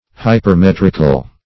Search Result for " hypermetrical" : The Collaborative International Dictionary of English v.0.48: Hypermetrical \Hy`per*met"ric*al\, a. Having a redundant syllable; exceeding the common measure.
hypermetrical.mp3